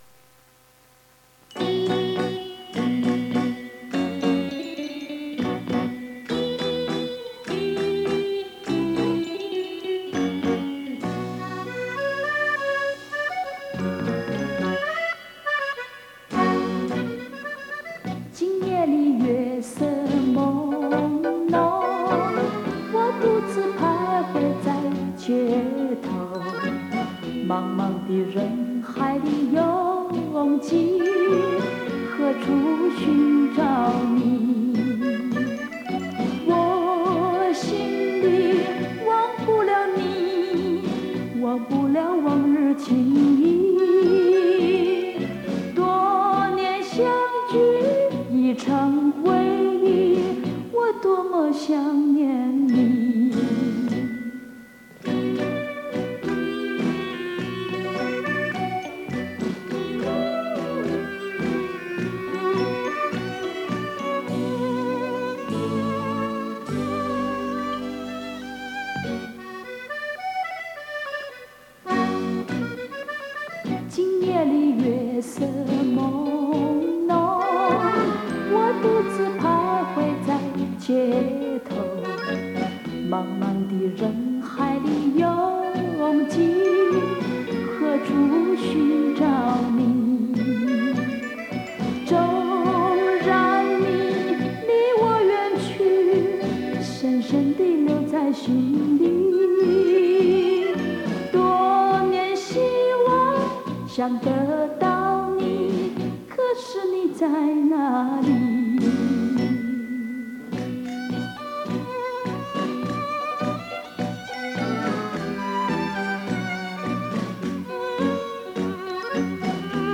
重新录制 原音重现